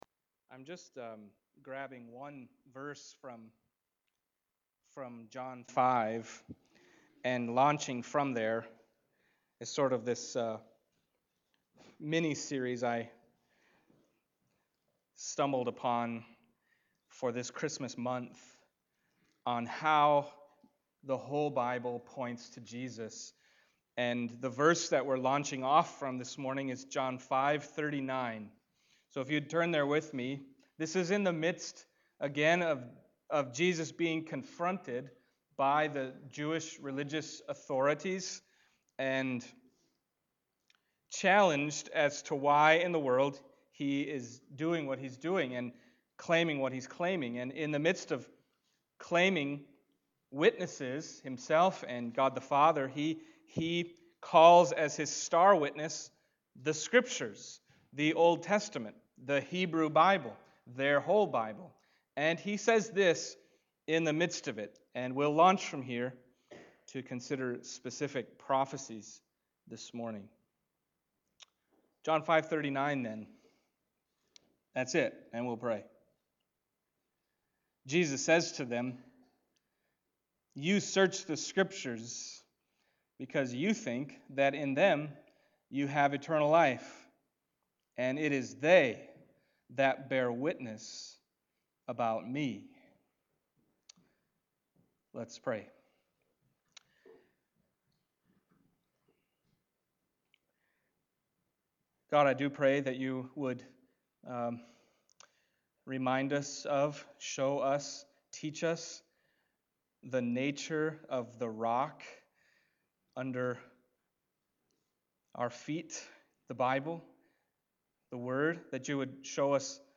John 5:39 Service Type: Sunday Morning John 5:39 « The Primary Purpose of the Whole Bible Jesus